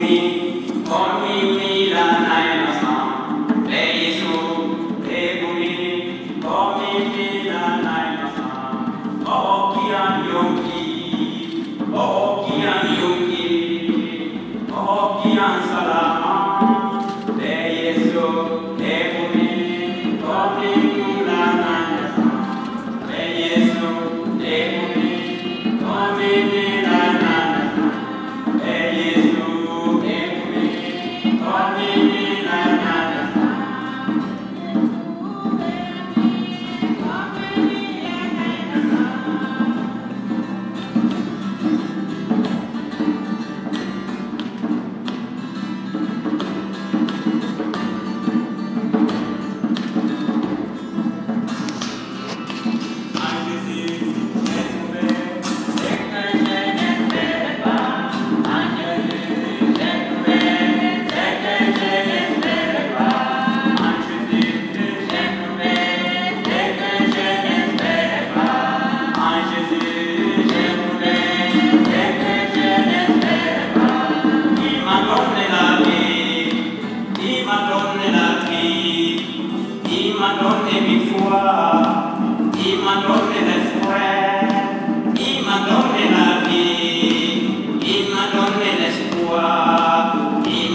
at jubilee service in Budolfi Cathedral, Aalborg.